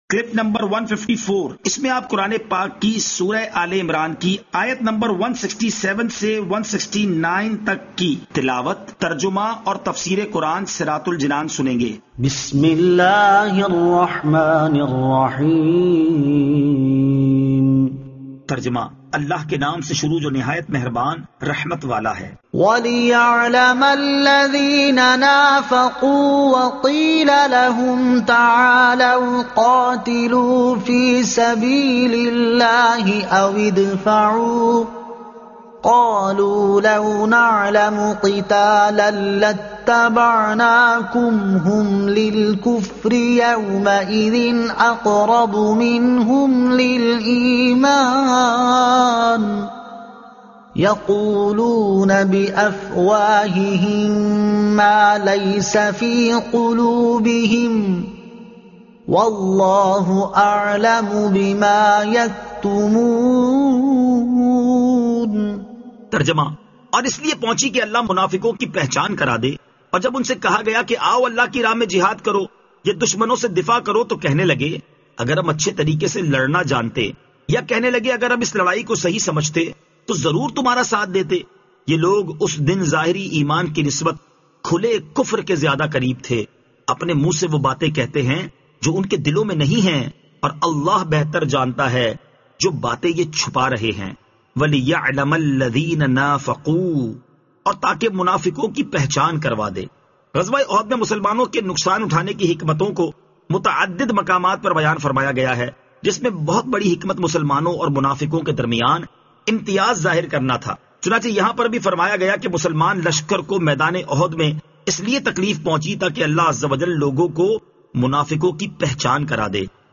Surah Aal-e-Imran Ayat 167 To 169 Tilawat , Tarjuma , Tafseer